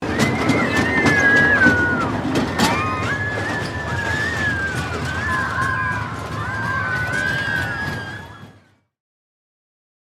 Free SFX sound effect: Kiddie Coaster.
Kiddie Coaster
yt_7XF4r_FvdRY_kiddie_coaster.mp3